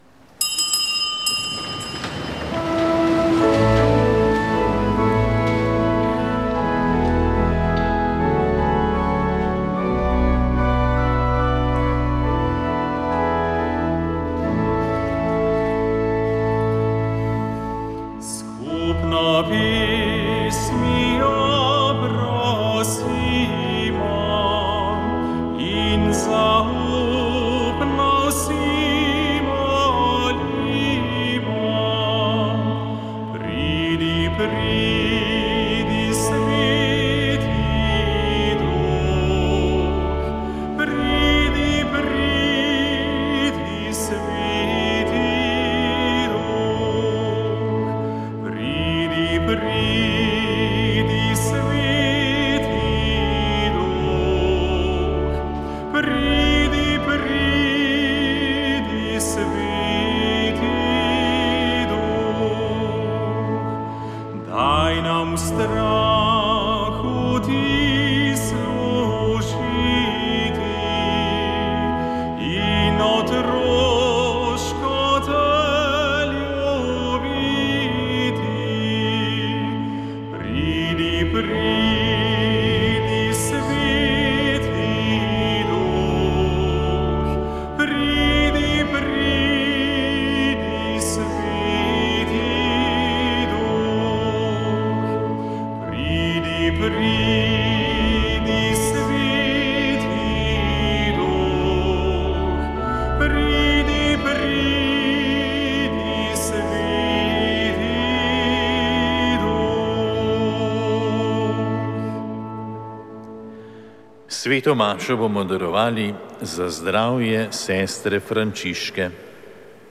Sveta maša
Sv. maša iz cerkve sv. Marka na Markovcu v Kopru 28. 4.